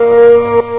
buzwarn.ogg